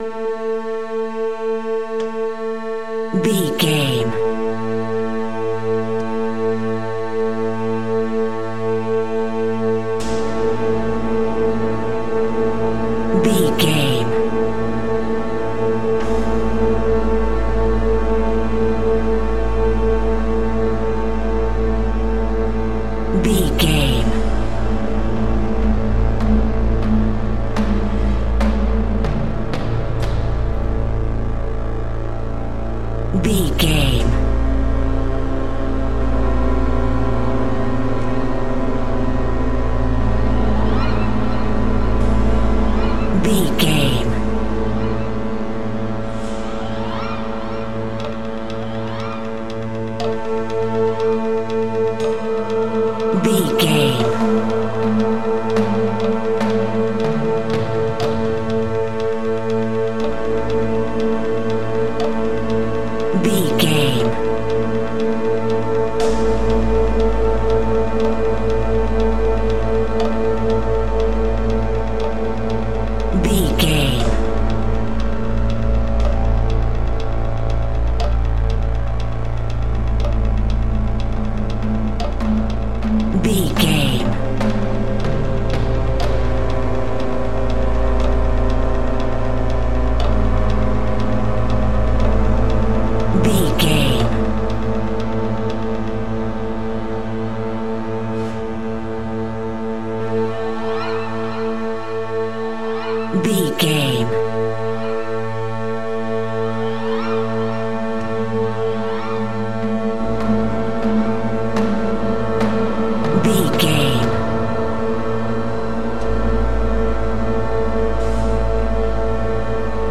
Eighties Slasher Horror Music.
In-crescendo
Thriller
Aeolian/Minor
scary
tension
ominous
dark
suspense
haunting
eerie
strings
synthesiser
percussion
keyboards
ambience
pads